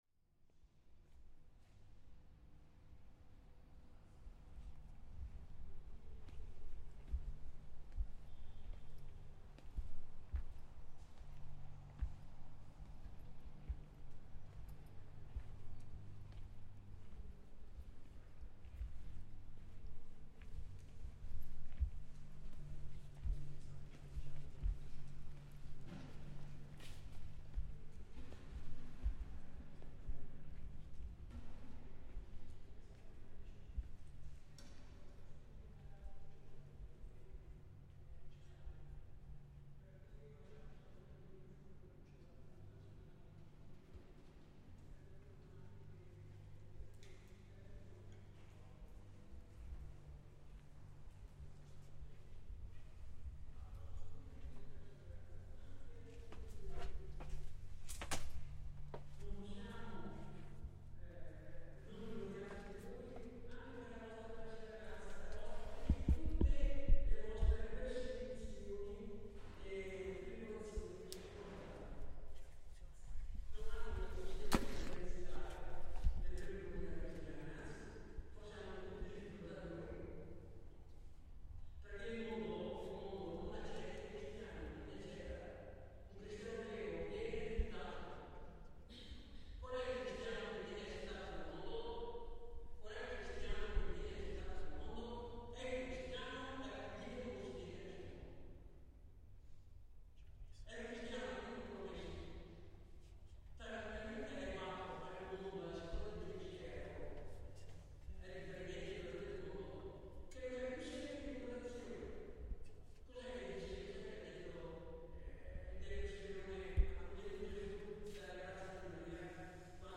Inside Treviso's cathedral, we record the space itself, before heading down to the crypt door, through which we can overhear a mass taking place, as if from a large distance away - we exit the cathedral to hear the different in the sonic ambience, as the traffic noise of the city immediately hits us anew.